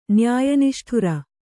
♪ nyāya niṣṭhura